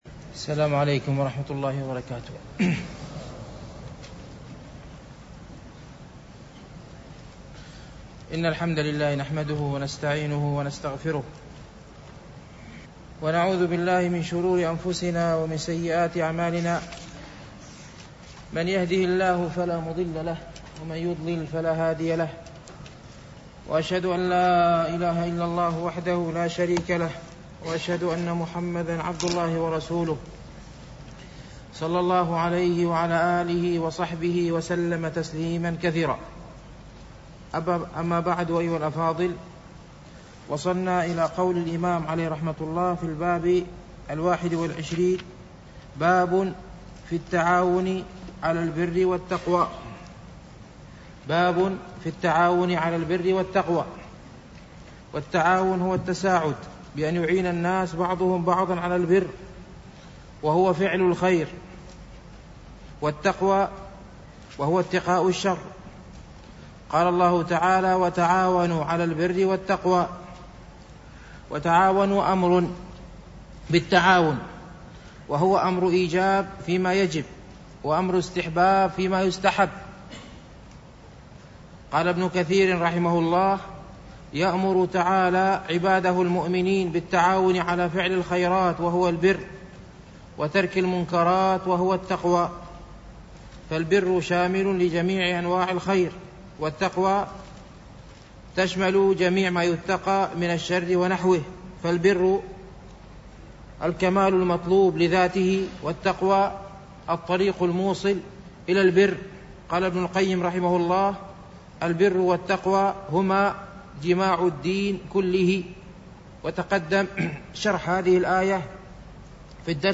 شرح رياض الصالحين - الدرس الخامس والثلاثون